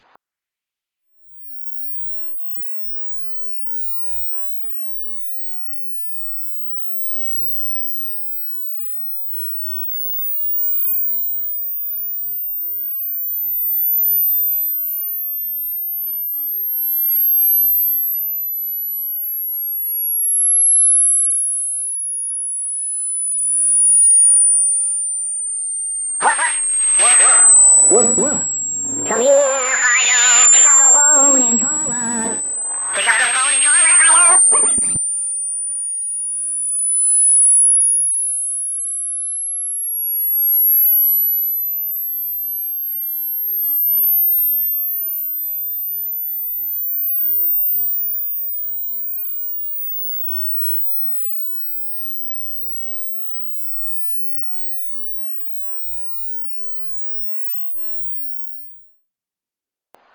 CAUTION: tape machine malfunctioned at the 27th second of the recording,
causing a sudden drop in pitch, but recovered a few seconds later. Most of this
should only be audible to your dog, not you. So please remember:
dogs.mp3